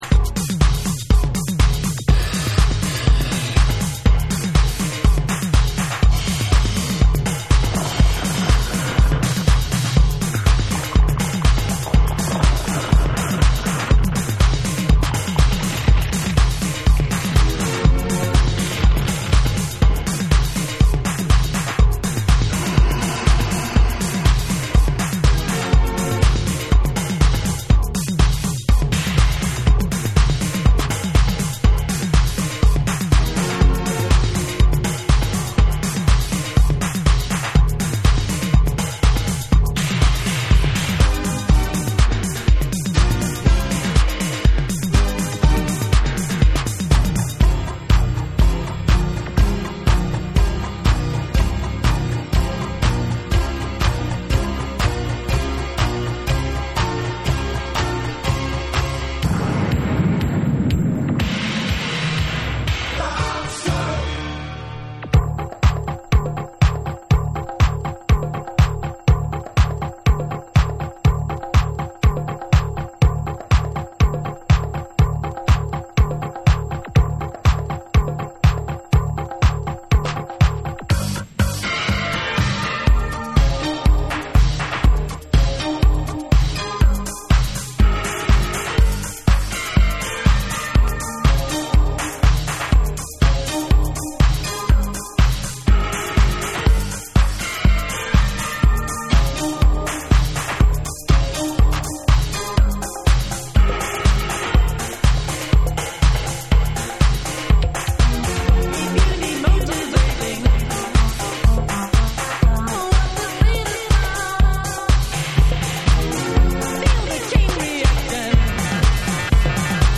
超絶ドラッギーに仕立てられたNWディスコ・ナンバー
TECHNO & HOUSE / RE-EDIT / MASH UP